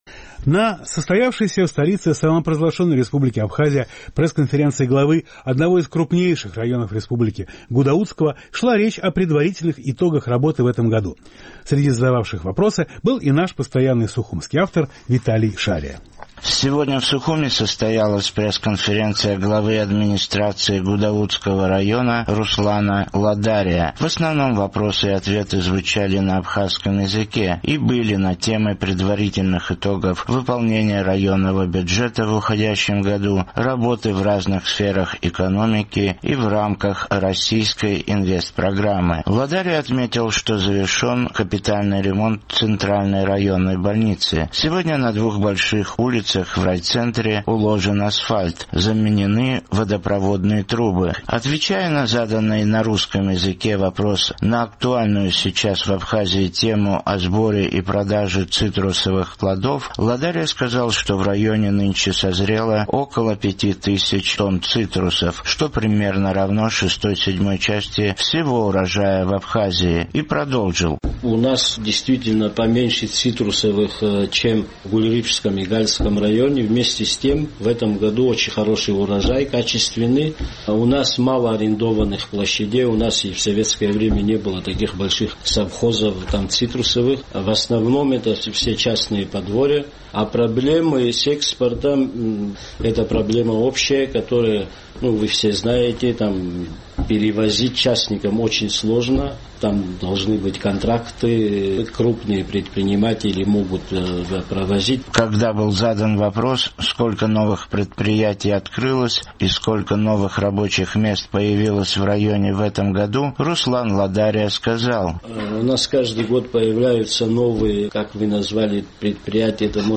Сегодня в Сухуме состоялась пресс-конференция главы администрации Гудаутского района Руслана Ладария. В основном вопросы касались выполнения районного бюджета в уходящем году, работы в разных сферах экономики и в рамках российской Инвестпрограммы.